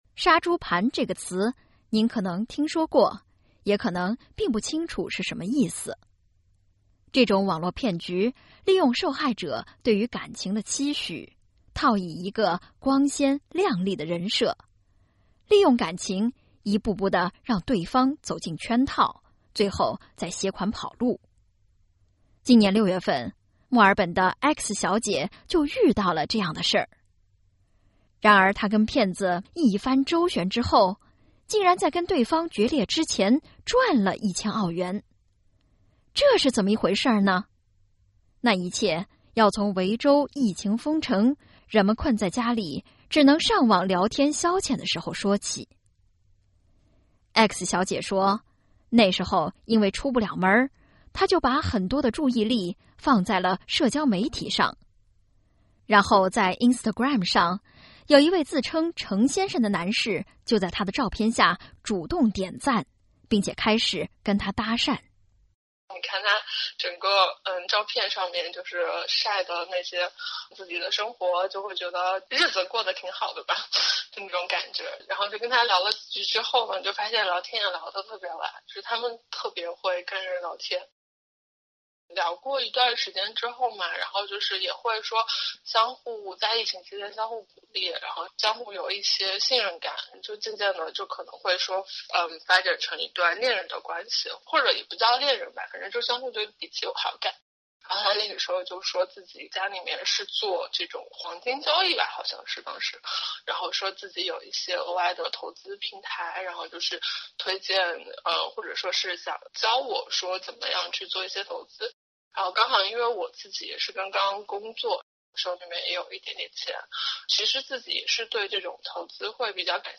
文中X小姐为受访者化名，应本人要求受访者声音经过处理。